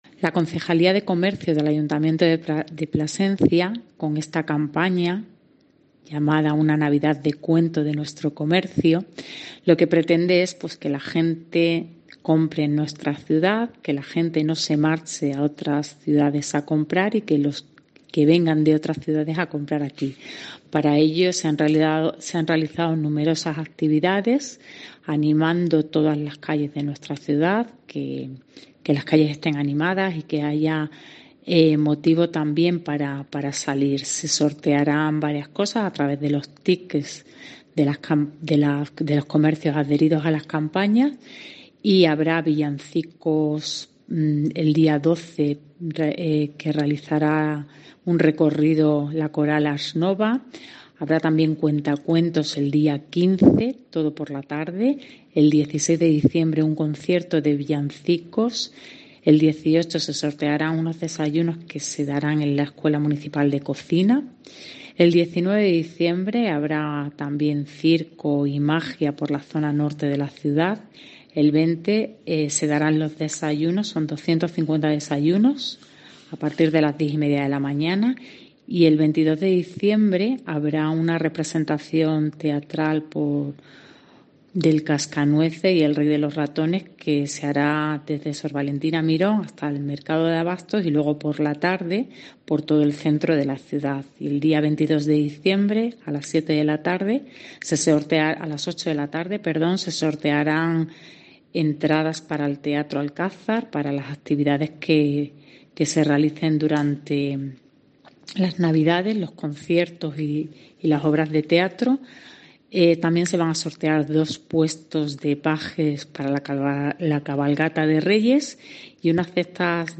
Belinda Martín, Concejala de Comercio del Ayuntamiento de Plasencia